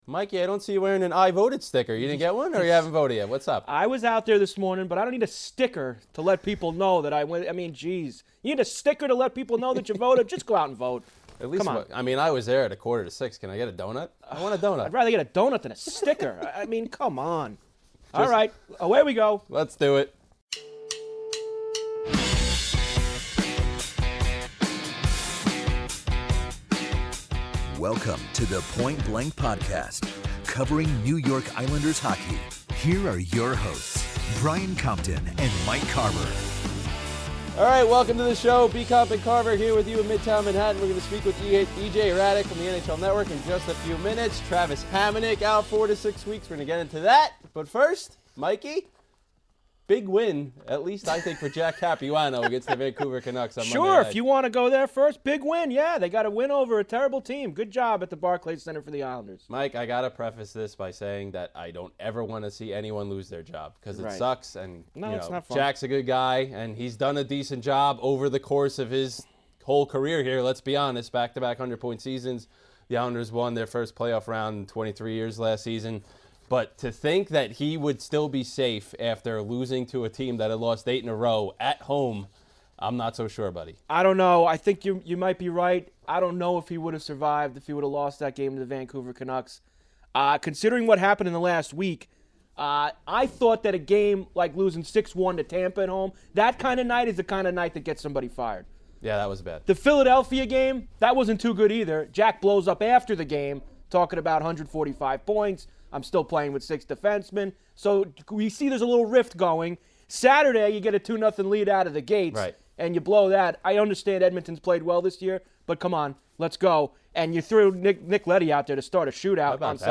Later, EJ Hradek of the NHL Network calls in to discuss Capuano’s future with the team and Garth Snow’s offseason moves.